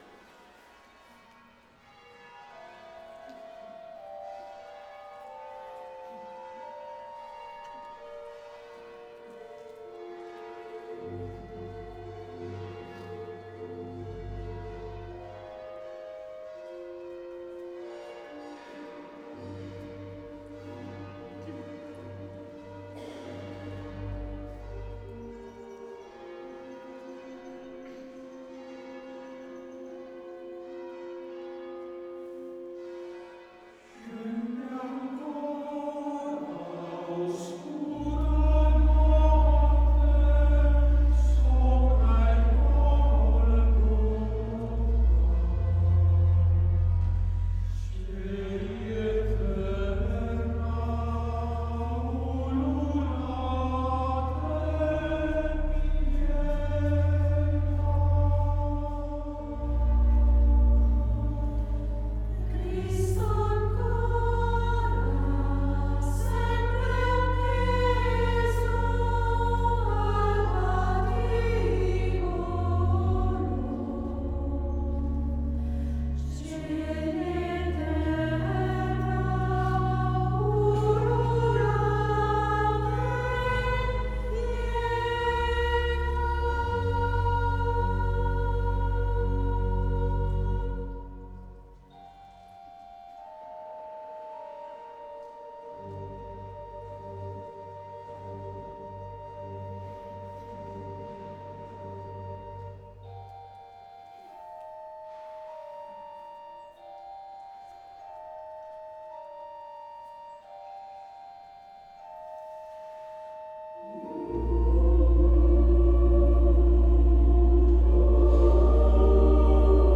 Elevazione Musicale Santa Pasqua 2025.
Basilica di S.Alessandro in Colonna, Bergamo
5 voci miste e organo